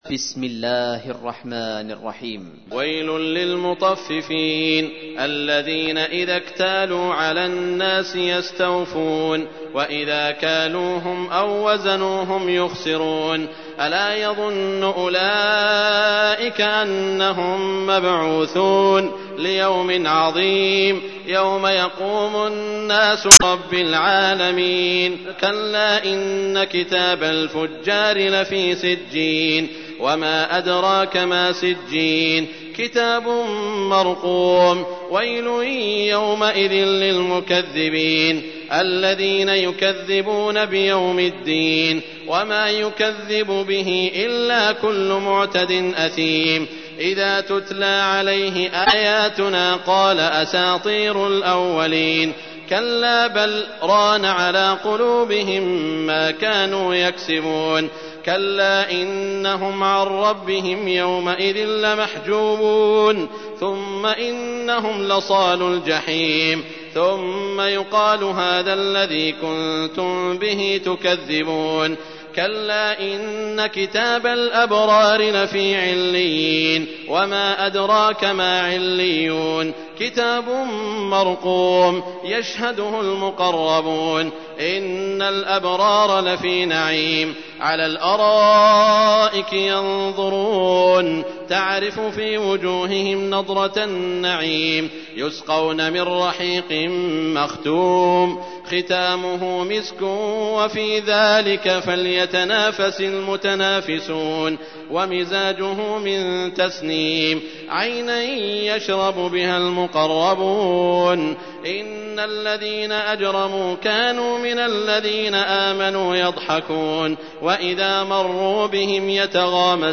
تحميل : 83. سورة المطففين / القارئ سعود الشريم / القرآن الكريم / موقع يا حسين